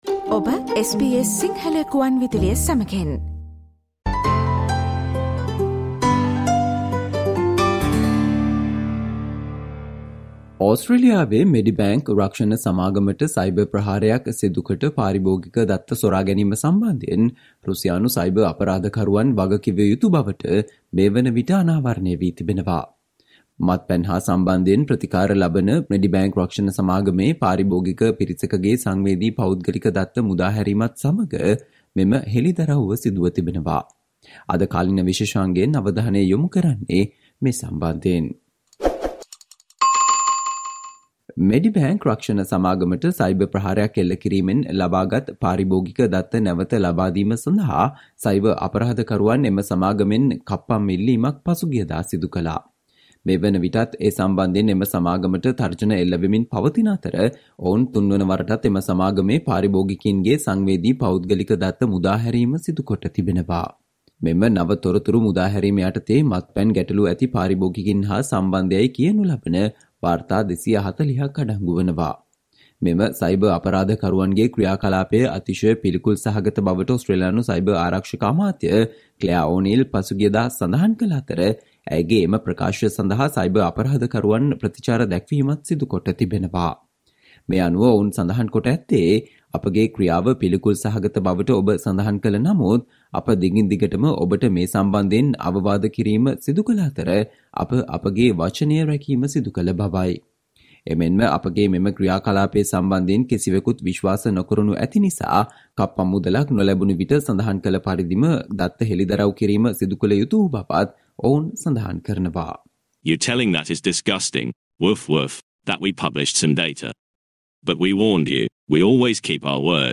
Today - 14 November, SBS Sinhala Radio current Affair Feature on AFP identifies suspects behind Medibank data breach